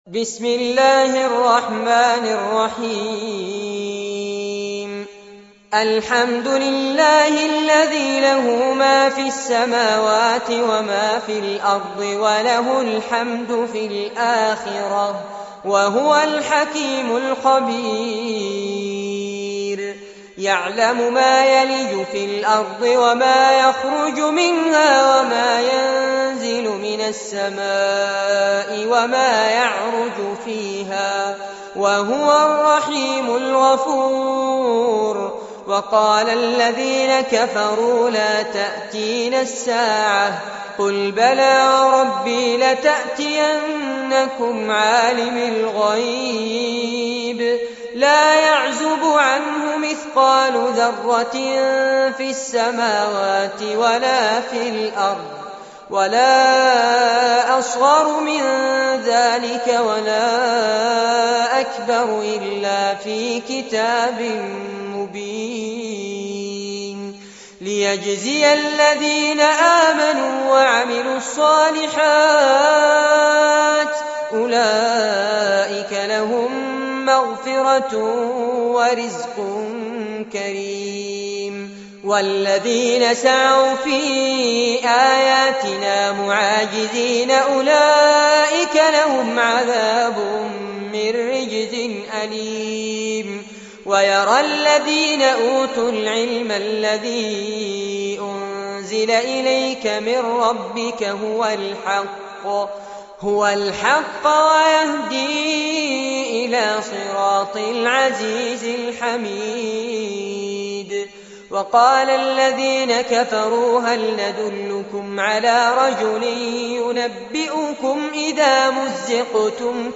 سورة سبأ- المصحف المرتل كاملاً لفضيلة الشيخ فارس عباد جودة عالية - قسم أغســـــل قلــــبك 2